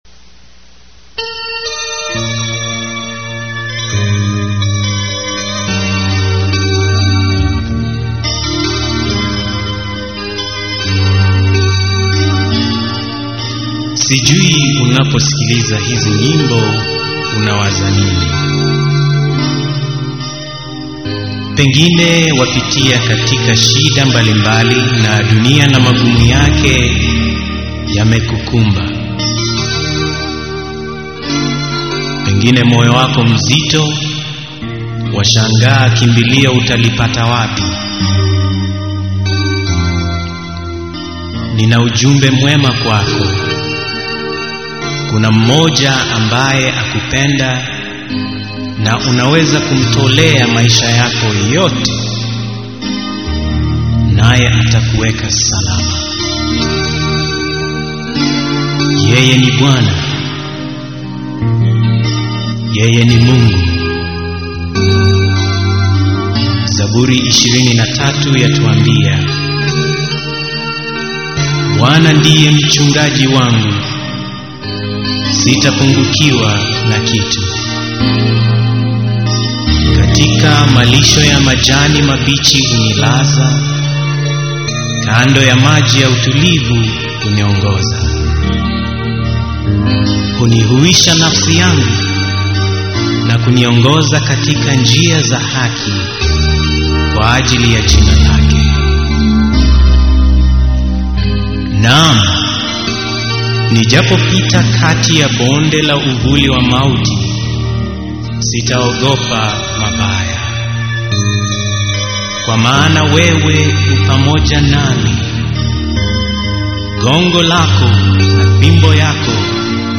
Swahili Songs
This music is all Christian music.